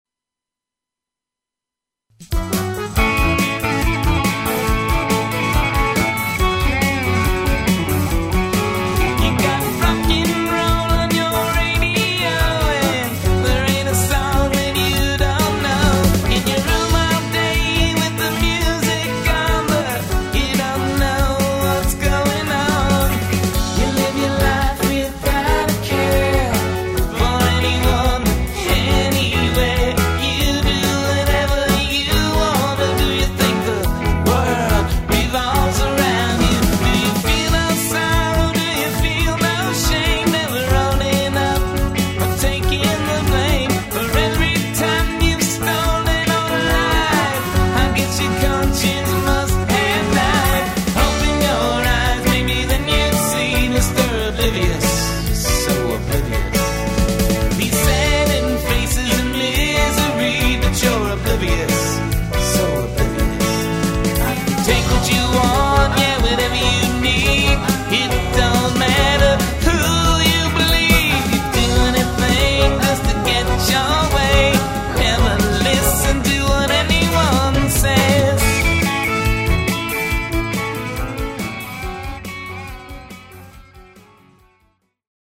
Fender Telecaster lead guitar
Neo-Americana music